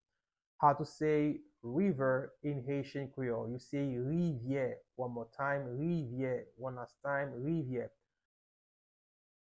13.-How-to-say-River-in-Haitian-Creole-–-Rivye-with-pronunciation.mp3